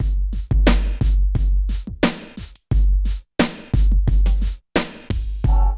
64 DRUM LP-R.wav